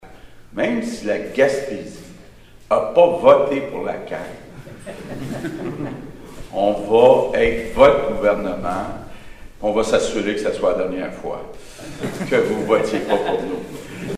Lors de son allocution lundi à L’Anse-au-Griffon, François Legault a fait cette déclaration :